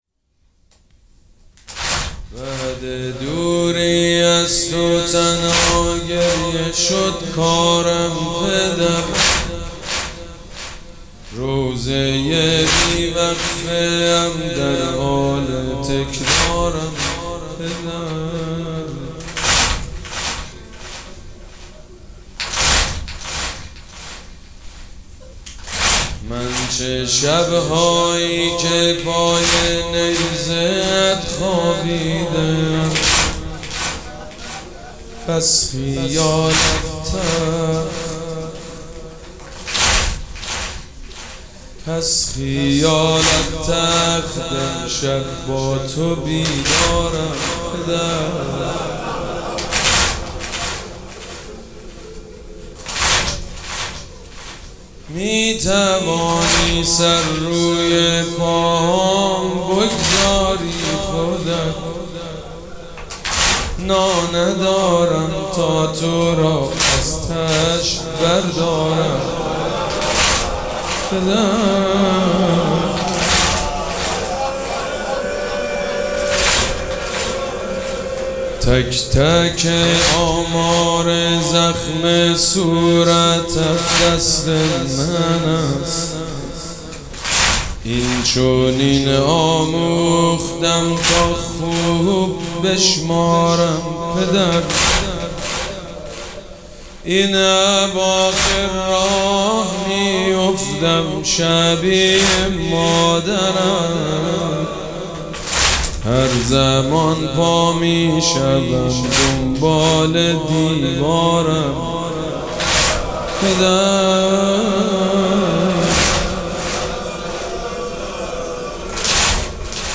مراسم عزاداری شب سوم محرم الحرام ۱۴۴۷
مداح